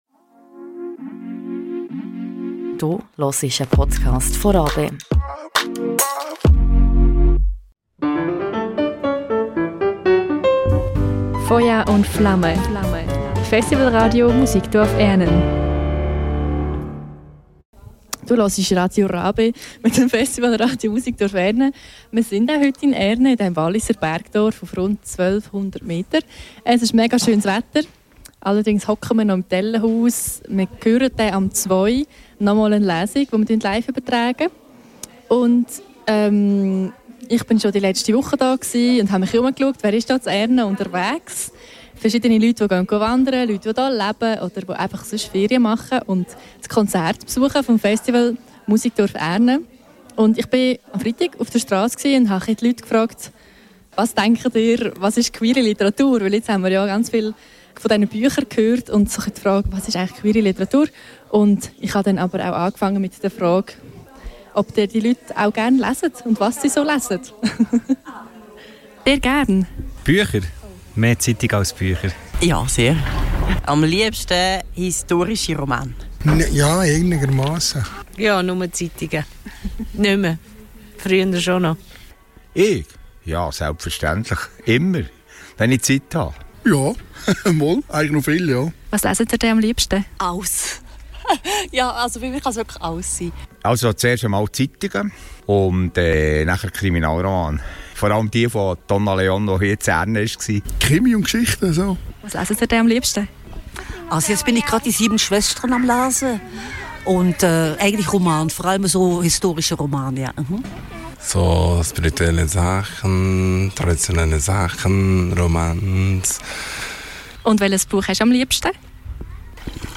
Diese Frage stellte das Team vom Festivalradio Musikdorf Ernen Passant*innen auf den Strasse vom Oberwalliser Dorf Ernen. Und Bettina Böttinger, Moderatorin vom Queerlesen, beantwortet diese und weitere Fragen zu queerer Literatur im Interview. Am Queerlesen (früher: Querlesen) in Ernen lesen seit rund 20 Jahren queere Schriftsteller*innen aus ihren neusten Werken.